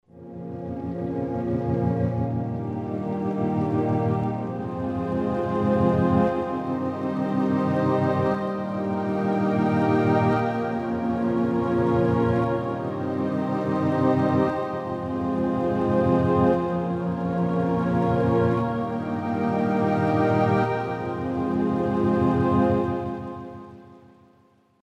Class: Synth Module
REVIEW "Fun unit with very lo fidelity sounds. Some very good textures out of PCM and FM syntheses."